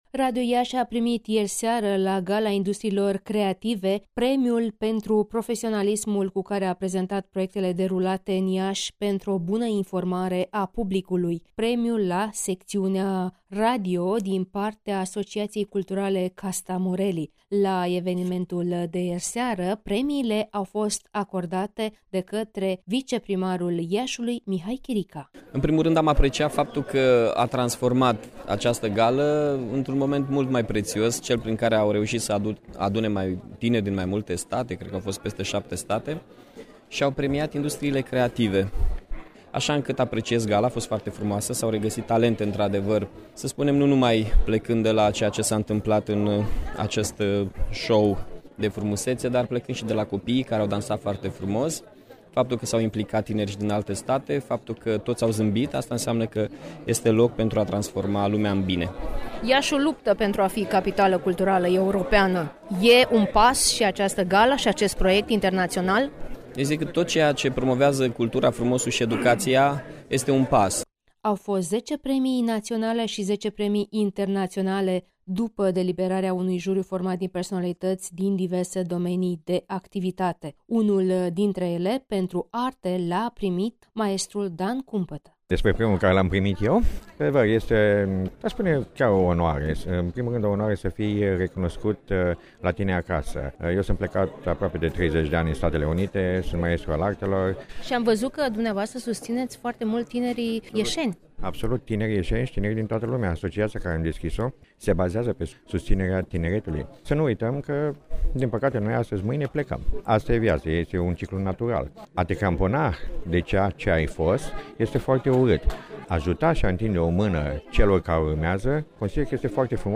Consiliul de Conducere al Asociaţiei culturale Kasta Morrely a organizat ieri seară, la Ateneul Tătăraşi din Iaşi,  „Gala Premiilor în Industriile Creative”, eveniment de recunoaştere a valorilor din industriile creative.